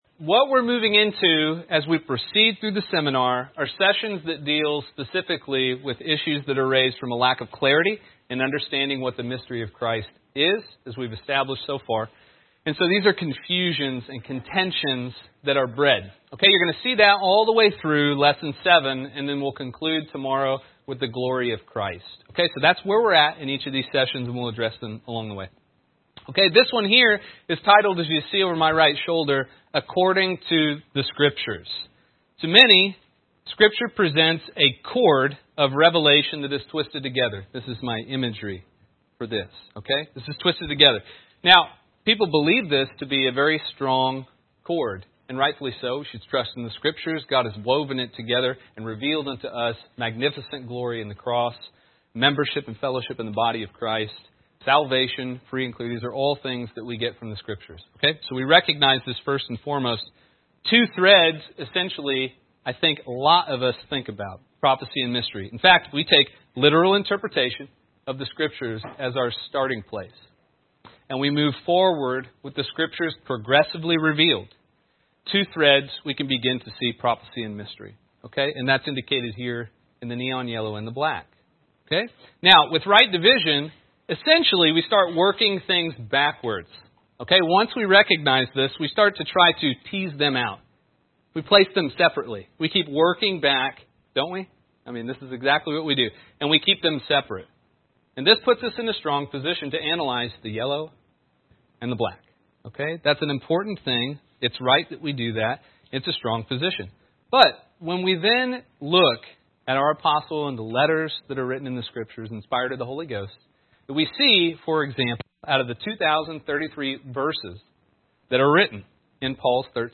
Download MP3 | Download Outline Description: Our 2019 Seminar, “Mystery Made Clear,” was taught over the weekend of October 19th and 20th. In our fourth session, we learned that there are facts, or truths, in all scripture upon which the mystery relies, or upon which it is built. Said differently, the content of the mystery kept secret since the beginning of the world requires the content of prophecy revealed since the beginning of the world.